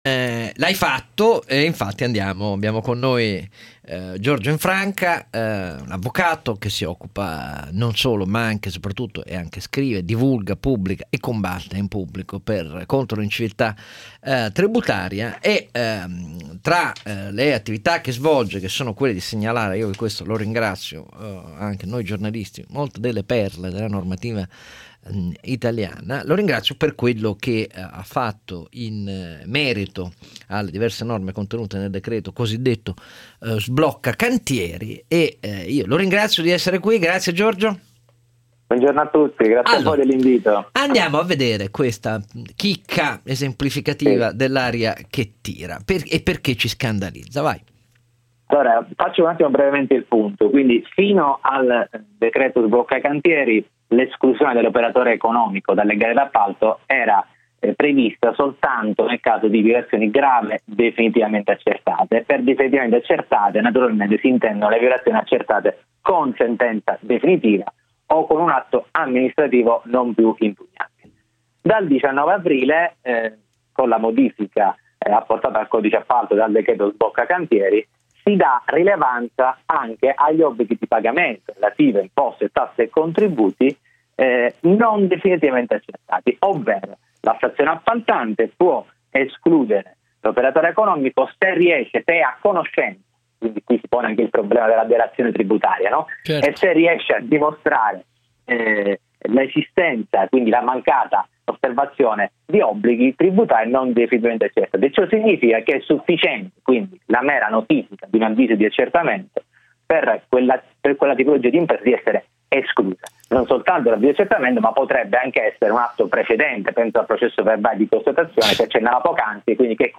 programma trasmesso da Radio 24